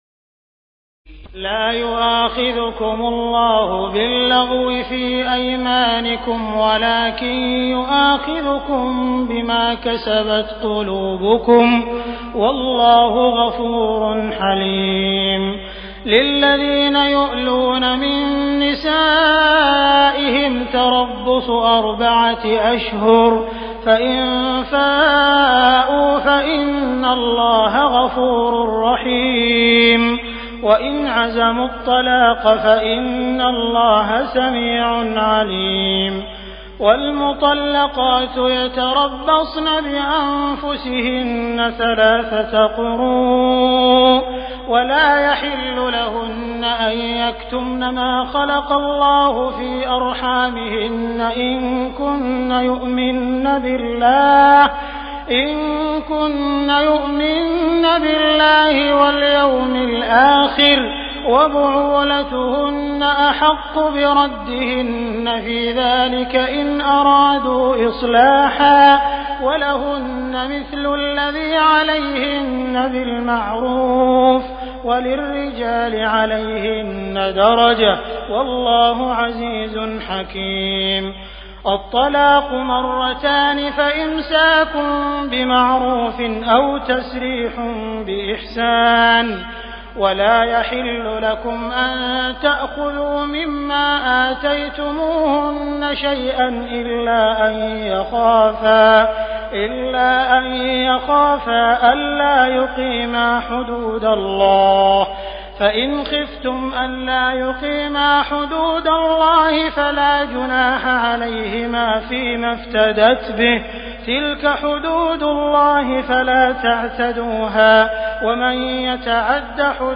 تراويح الليلة الثالثة رمضان 1418هـ من سورة البقرة (225-269) Taraweeh 3st night Ramadan 1418H from Surah Al-Baqara > تراويح الحرم المكي عام 1418 🕋 > التراويح - تلاوات الحرمين